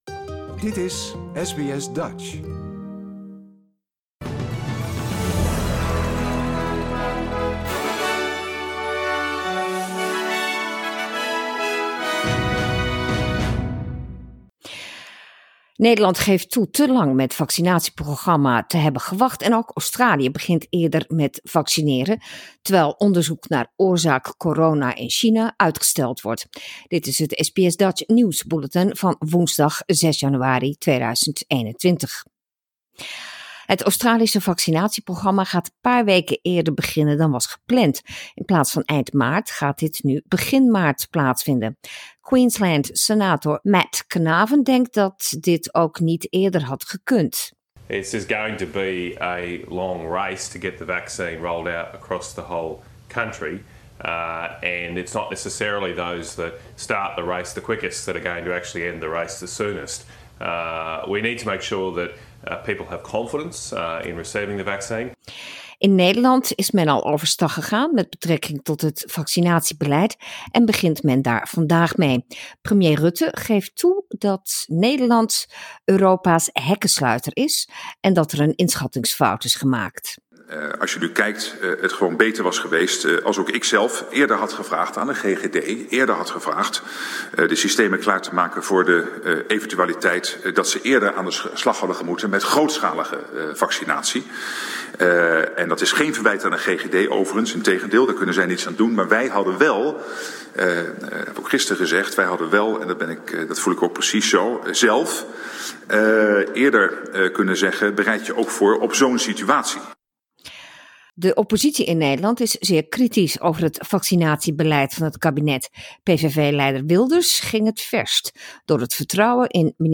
Nederlands/Australisch SBS Dutch nieuwsbulletin woensdag 6 januari 2021